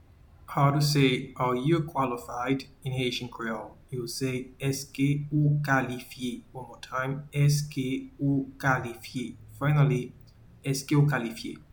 Pronunciation:
Are-you-qualified-in-Haitian-Creole-Eske-ou-kalifye.mp3